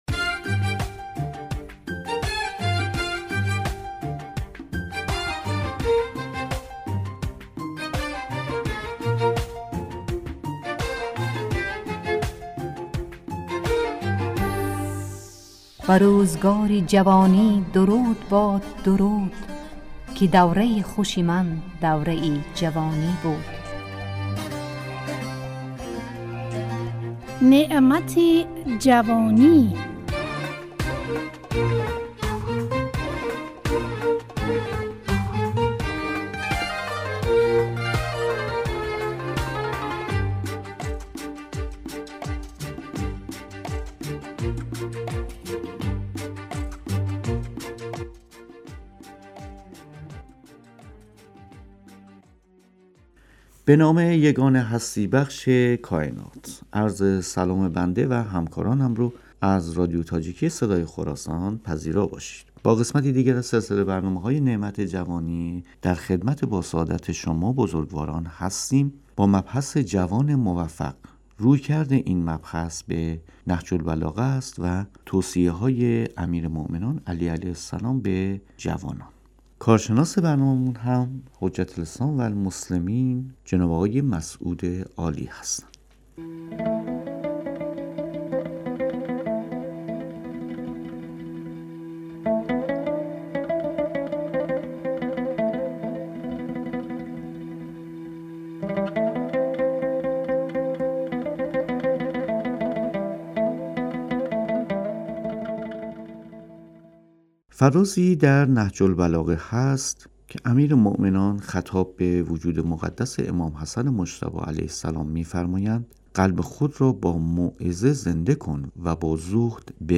نعمت جوانی، برنامه ای از گروه اجتماعی رادیو تاجیکی صدای خراسان است که در آن، اهمیت این دوران باشکوه در زندگی انسان مورد بررسی قرار می گیرد.